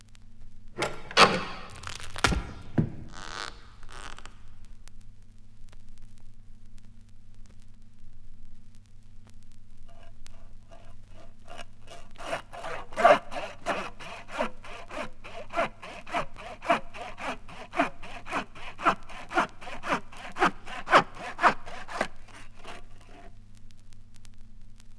• head chopped off - sawing.wav
head_chopped_off_-_sawing_RJW.wav